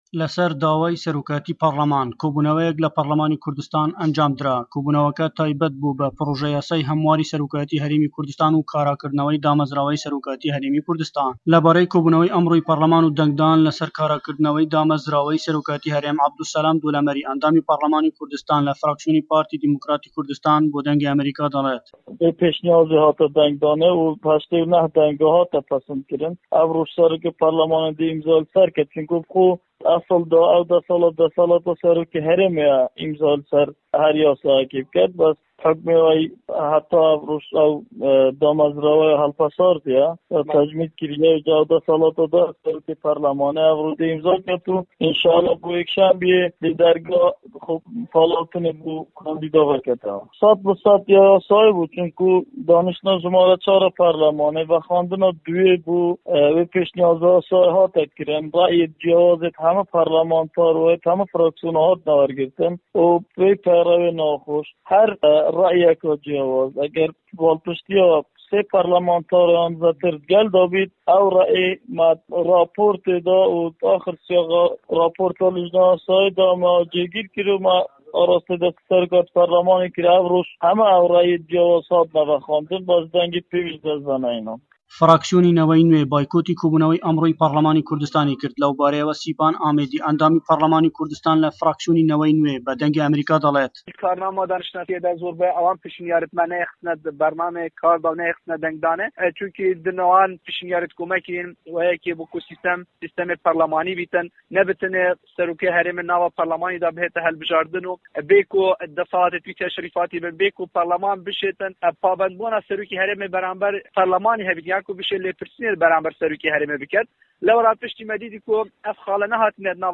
عەبدولسەلام دۆڵەمەڕی، ئەندامی پەرلەمانی هەرێمی کوردستان لە فراکسیۆنی پارتی دیموکراتی کوردستان و سیپان ئامێدی، ئەندامی پەرلەمانی هەرێمی کوردستان لە فراکسیۆنی جوڵانەوەی نەوەی نوێ، سەبارەت بە کاراکردنەوەی ئەم دامەزراوەیە بۆ دەنگی ئەمەریکا دەدوێن.